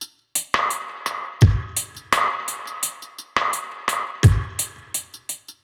Index of /musicradar/dub-drums-samples/85bpm
Db_DrumKitC_Wet_85-03.wav